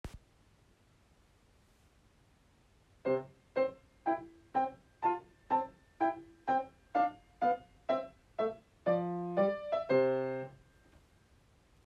音源①：ソフトペダルを踏まないヴァージョン